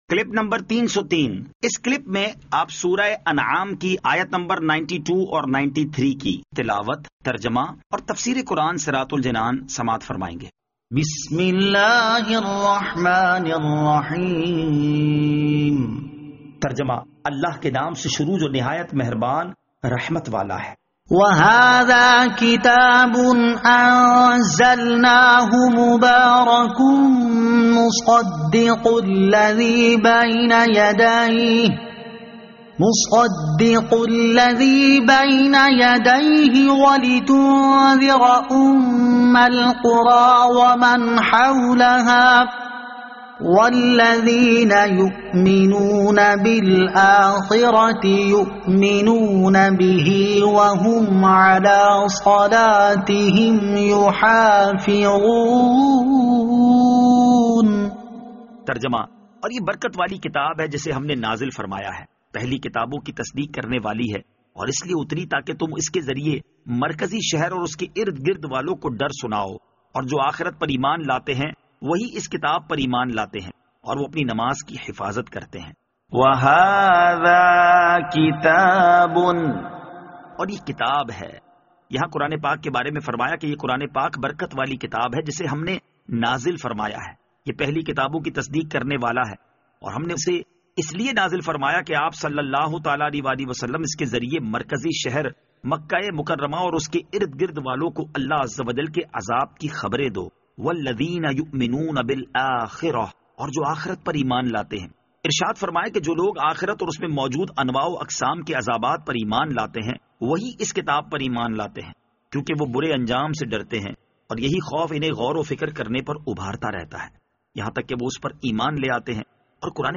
Surah Al-Anaam Ayat 92 To 93 Tilawat , Tarjama , Tafseer